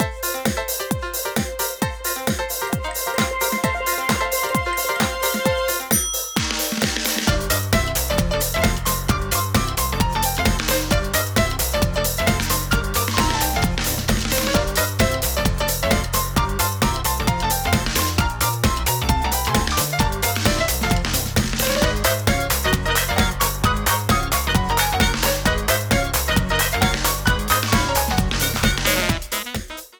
Genre Samba-jazz
Key F major